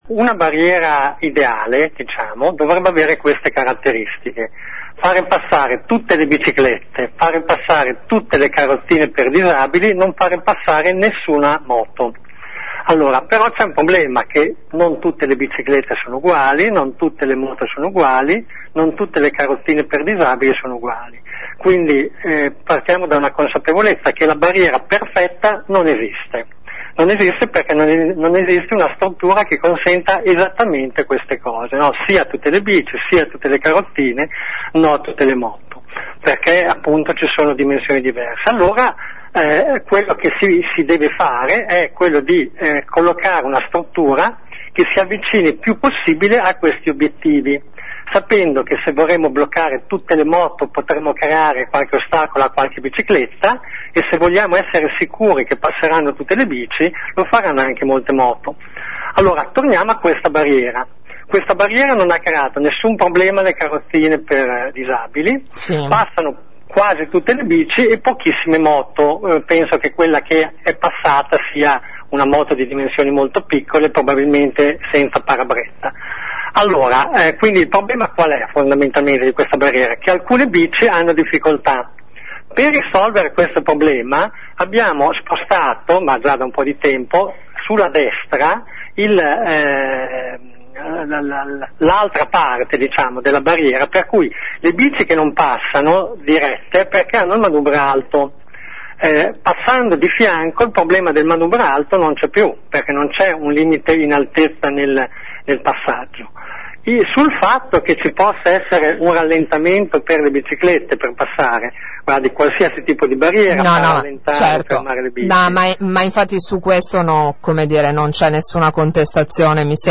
Siamo tornati a parlarne anche con il presidente del quartiere Roberto Fattori: “la barriera perfetta non esiste”